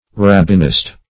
Search Result for " rabbinist" : The Collaborative International Dictionary of English v.0.48: Rabbinist \Rab"bin*ist\ (r[a^]b"b[i^]n*[i^]st), n. [Cf. F. rabbiniste.]